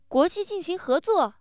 surprise